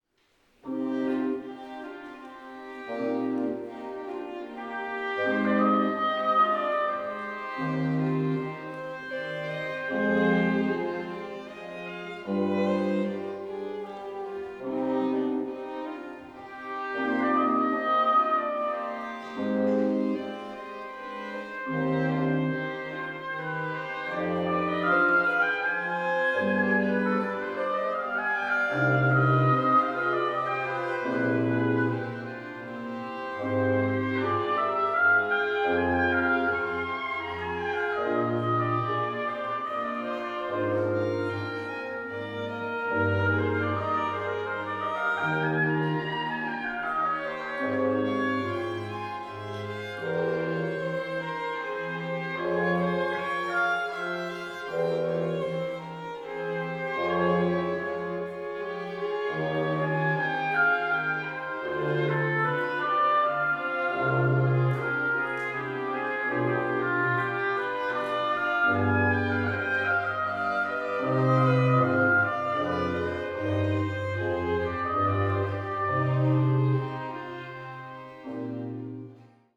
Ausschnitt aus der Arie:
Konzert vom 10.05.2015
Livemitschnitt